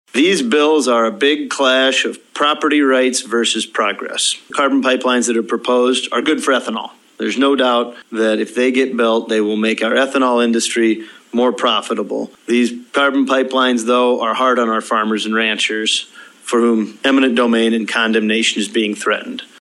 Republican House Majority Leader Will Mortenson from Pierre expects informative and emotional testimony when the legislature begins consideration of bills that restrict the private use of condemnation for rights of way. He says the issue puts legislators in a bind.